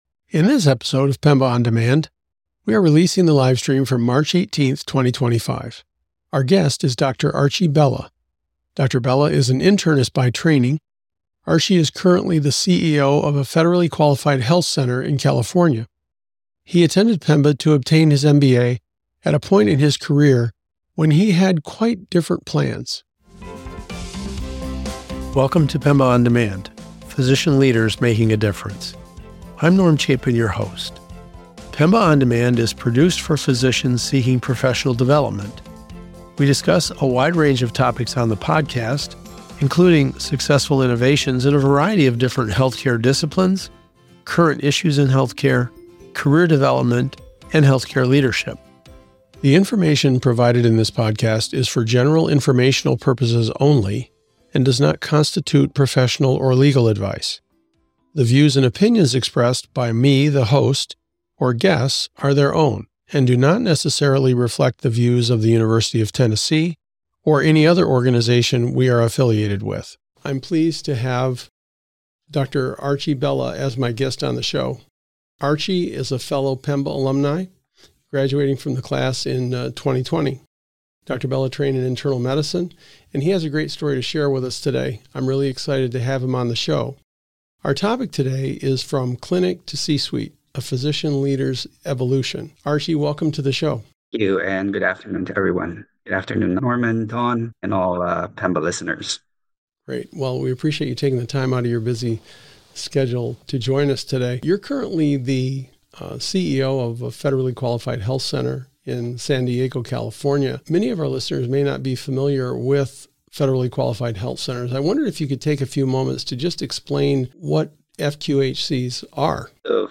a conversation about professional development for physicians